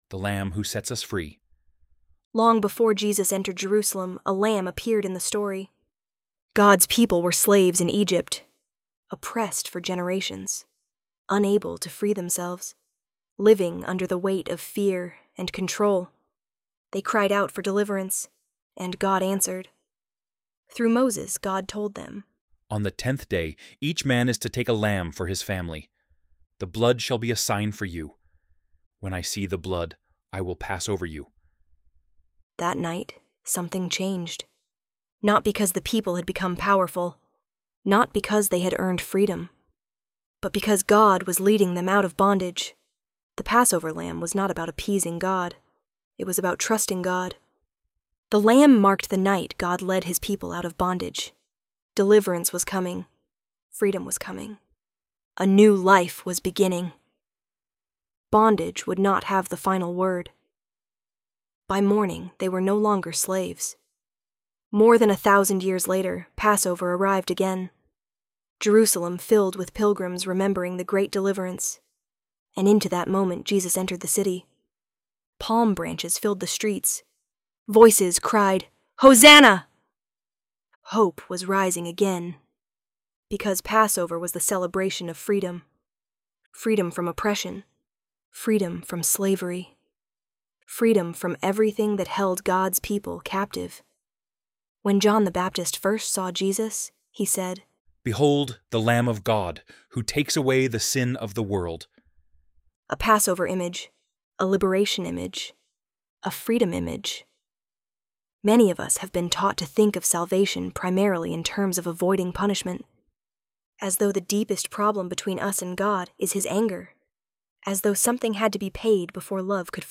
ElevenLabs_The_Lamb_Who_Sets_Us_Free.mp3